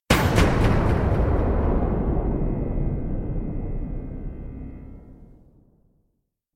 hugewave.mp3